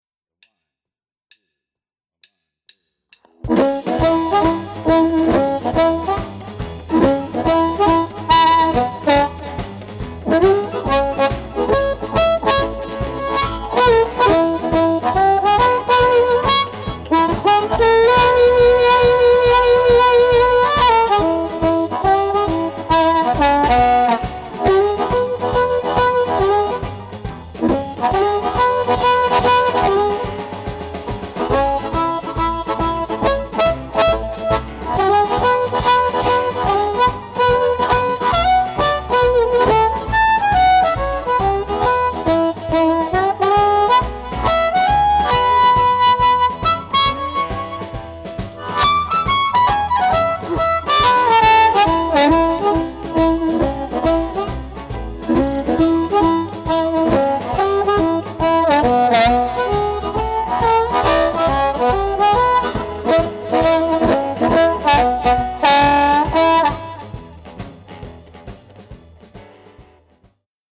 Amp Reviews | Blues Harmonica
Download the review sheet for each amp and the recorded mp3 files to compare the amps to make your choice of favorite amp.